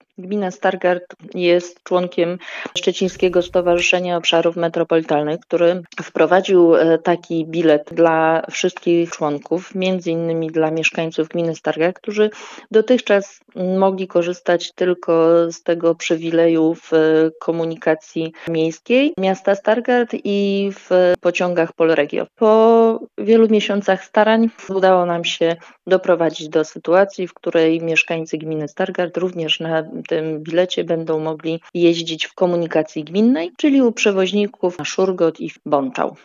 O szczegółach mówi wójt gminy Stargard Patrycja Gross: „ Gmina Stargard jest członkiem Szczecińskiego Stowarzyszenia Obszarów Metropolitalnych, które wprowadziło taki bilet dla wszystkich członków – między innymi dla mieszkańców gminy Stargard.